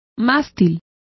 Complete with pronunciation of the translation of flagpole.